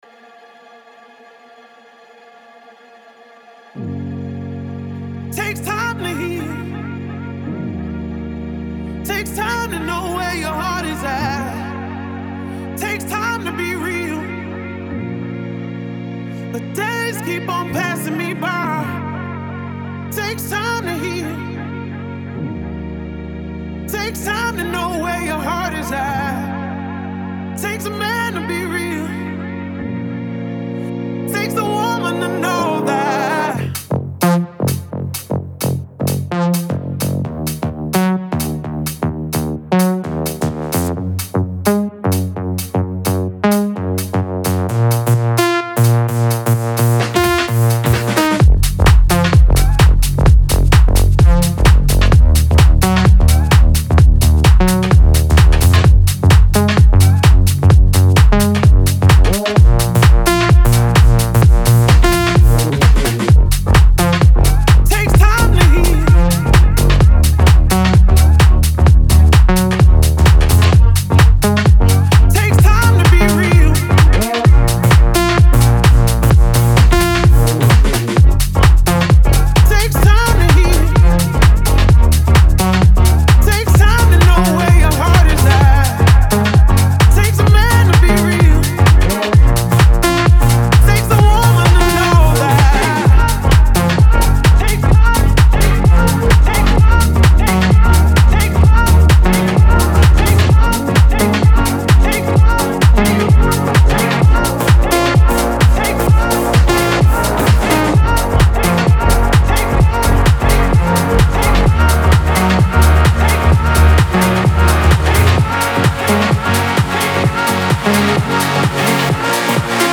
Genre : Dance